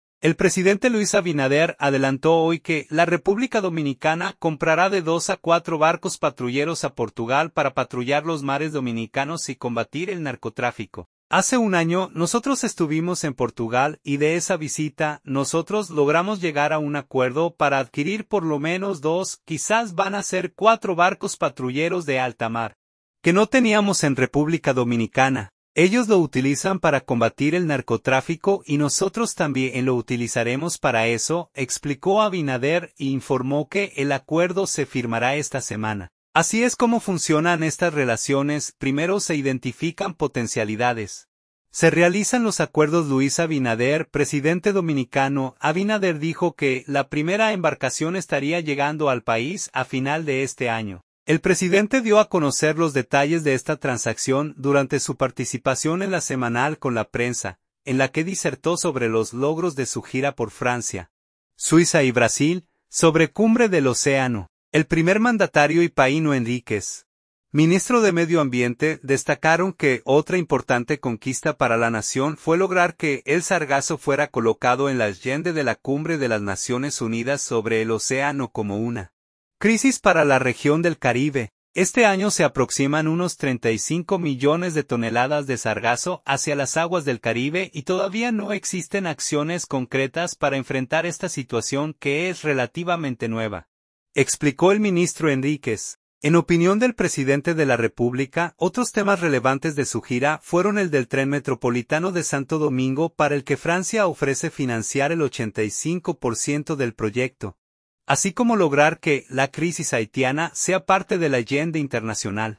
El presidente dio a conocer los detalles de esta transacción durante su participación en LA Semanal con la Prensa, en la que disertó sobre los logros de su gira por Francia, Suiza y Brasil.